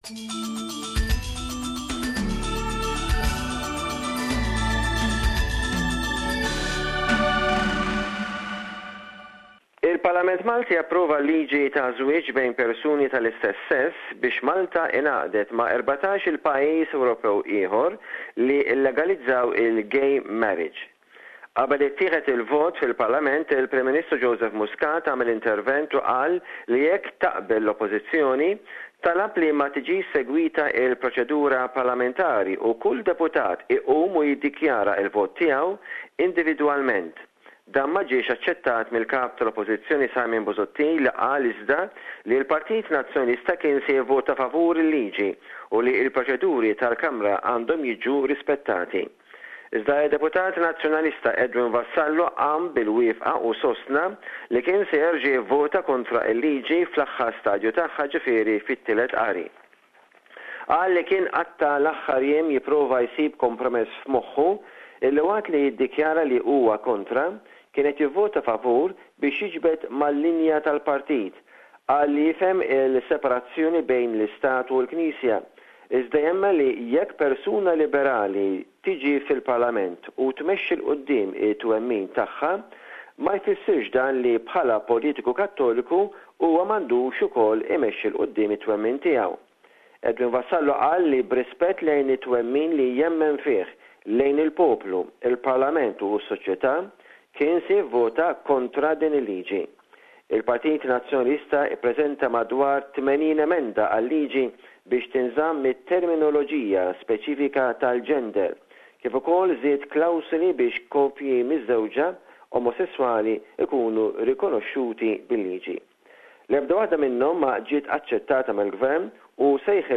reports from Valletta that Malta's parliament has voted in favour of same-sex couples to marry.